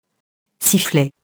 sifflet [siflɛ]